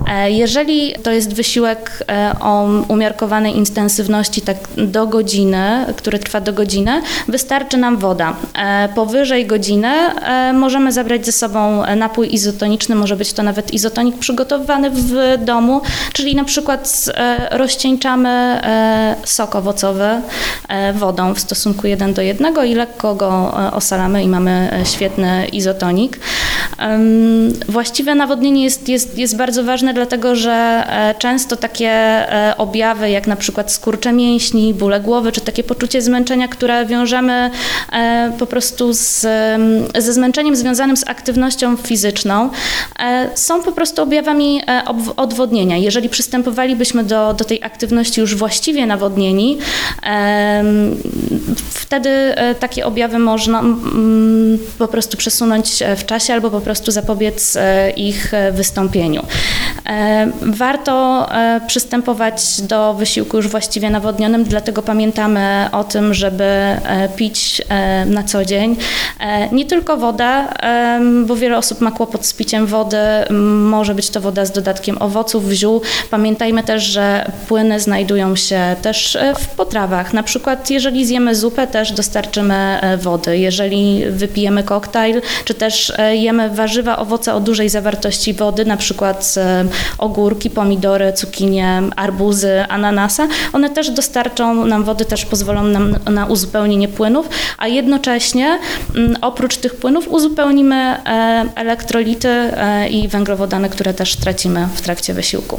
w rozmowie z dietetyczką